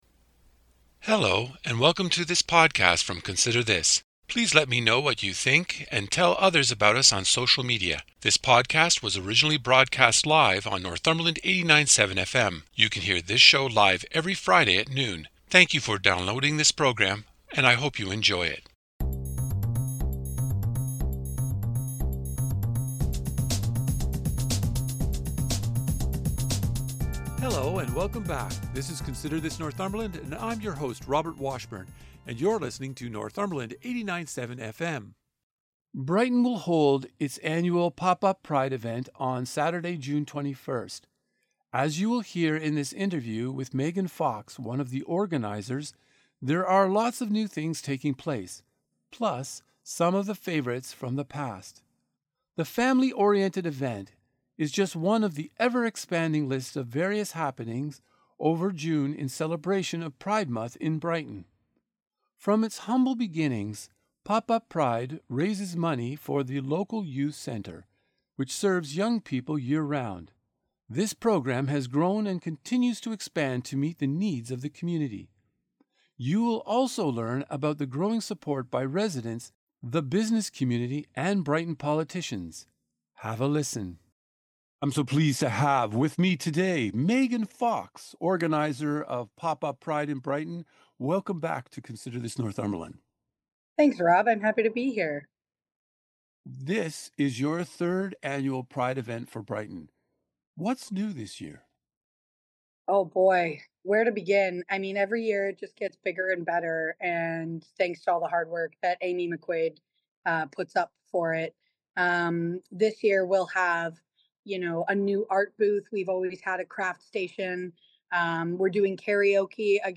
The full interview: